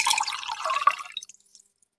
Preview and download these AI-generated food & drink sounds.
Pouring Water
Water being poured from a pitcher into a glass with rising pitch as it fills
pouring-water.mp3